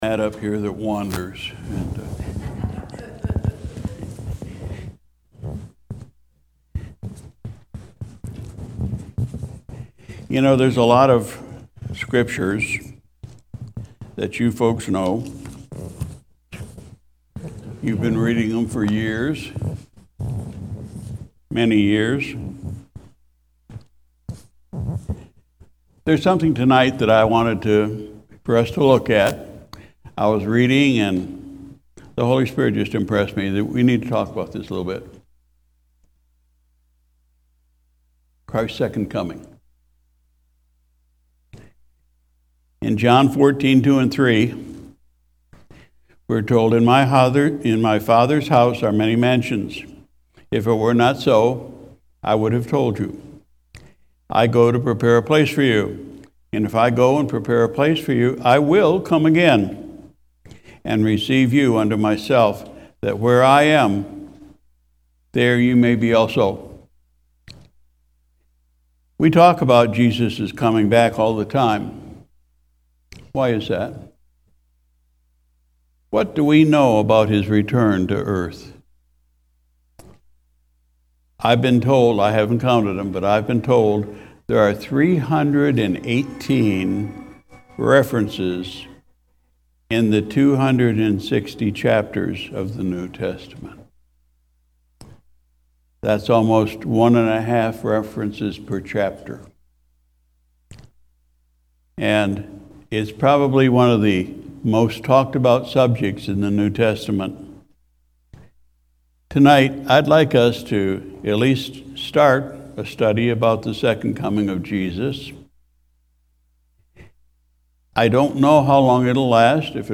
Second Coming of Christ , Sunday Evening Service